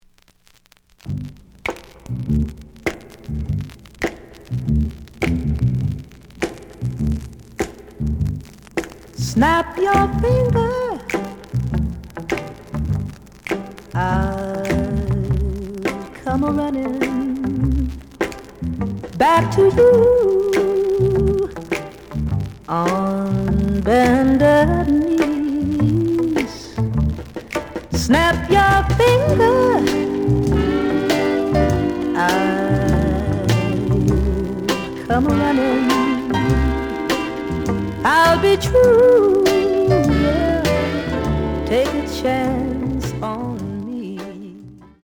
The audio sample is recorded from the actual item.
●Genre: Rhythm And Blues / Rock 'n' Roll
Some noise on A side.)